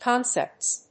/ˈkɑnsɛpts(米国英語), ˈkɑ:nsepts(英国英語)/